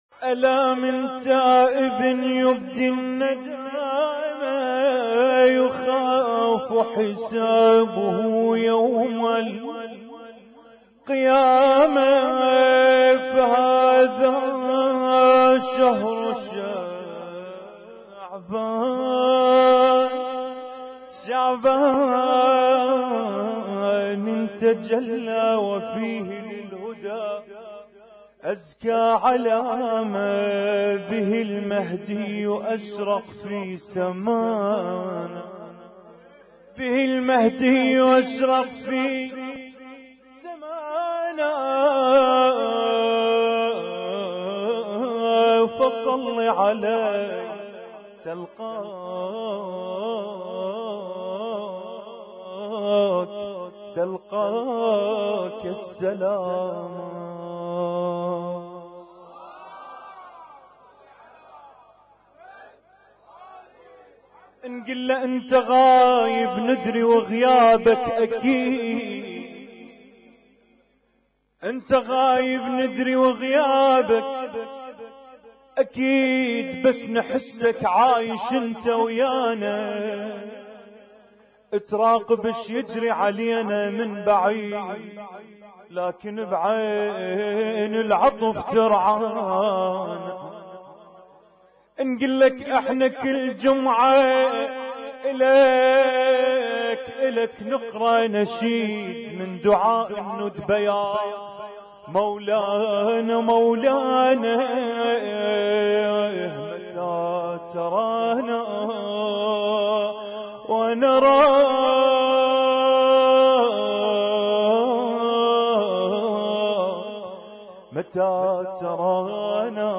اسم التصنيف: المـكتبة الصــوتيه >> المواليد >> المواليد 1438